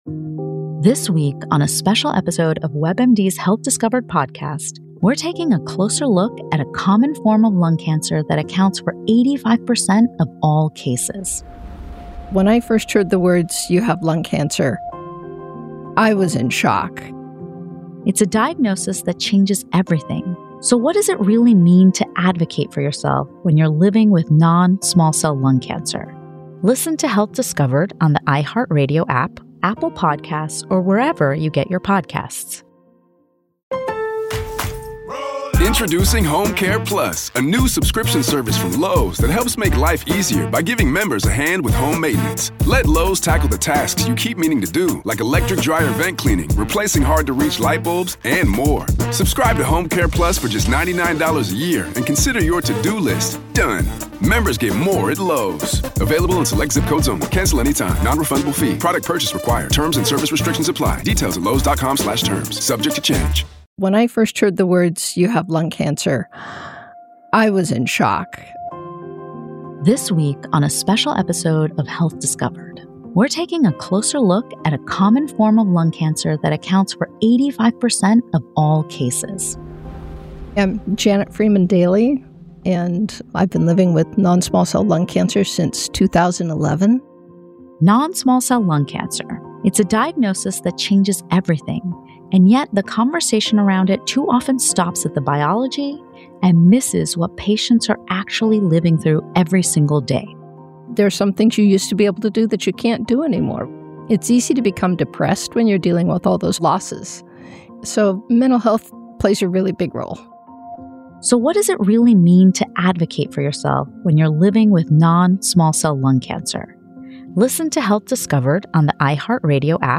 The Complete Interview